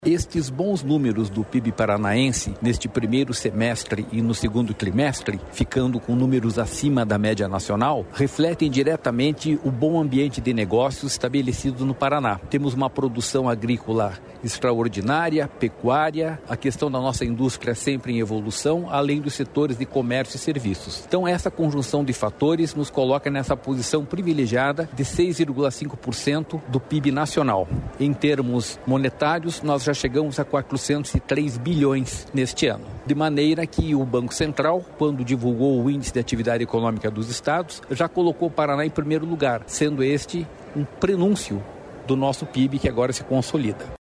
Sonora do diretor-presidente do Ipardes, Jorge Callado, sobre o PIB do Estado